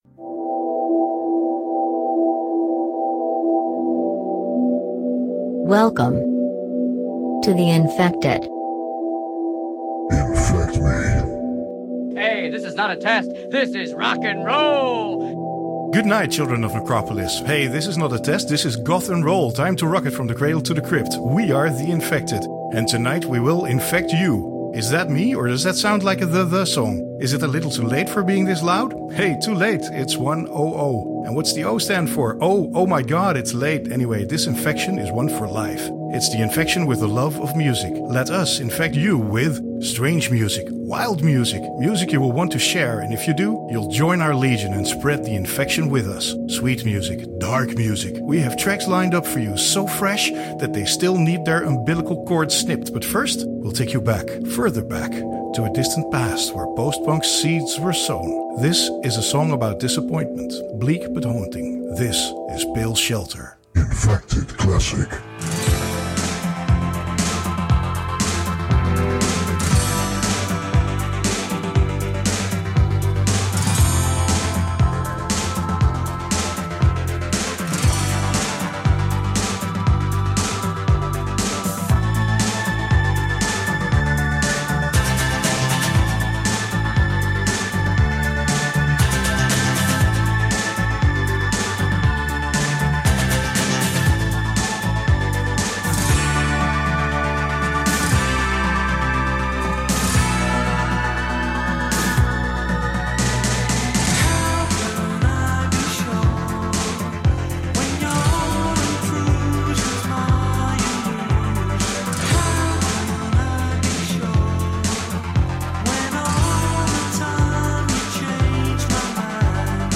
Great music from 1981 to 2019.